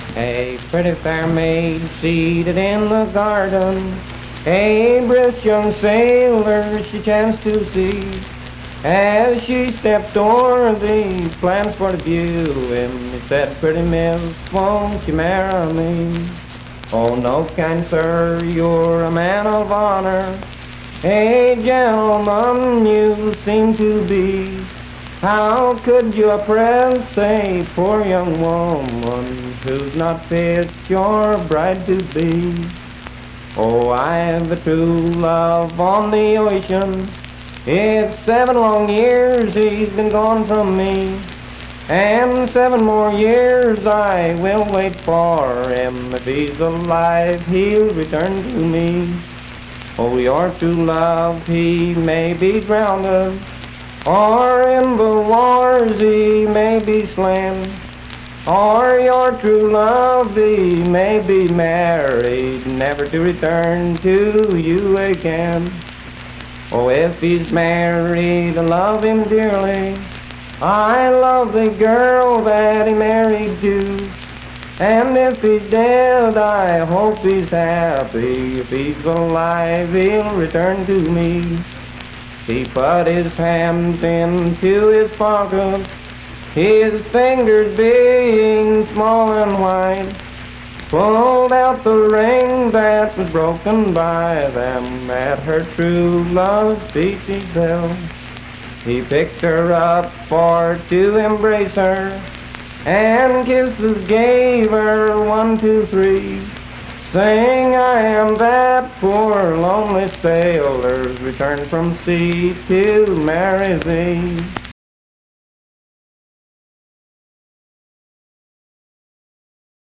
Unaccompanied vocal performance
Voice (sung)